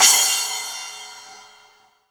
1SA CYMB.wav